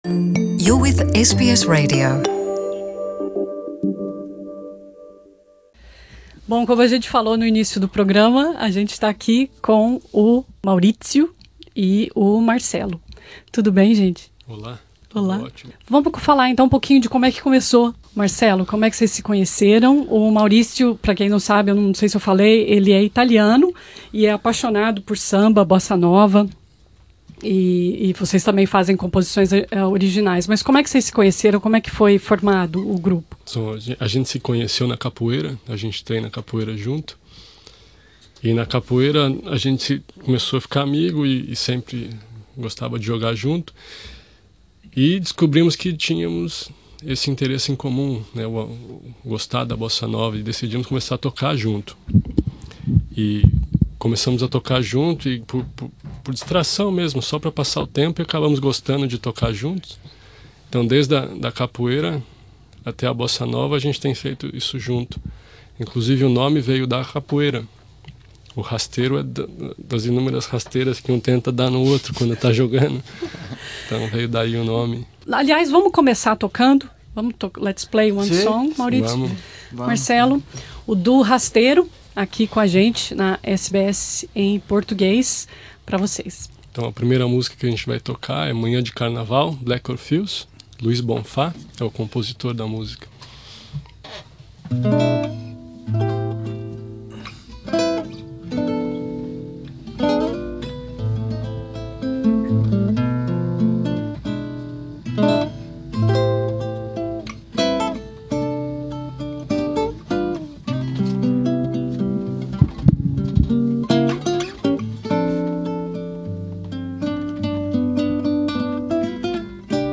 Nessa entrevista eles falam sobre a formação do grupo e as músicas que mais gostam de ouvir.